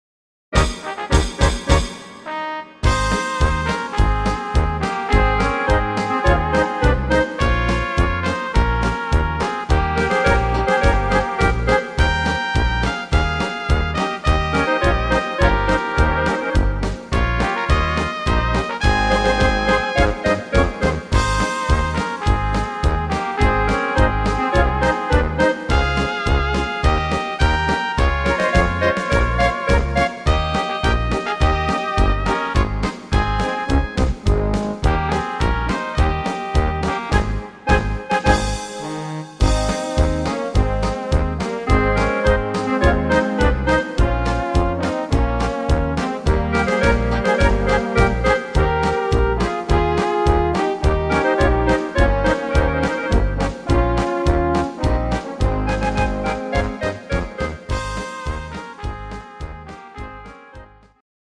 instrumental Blasmusik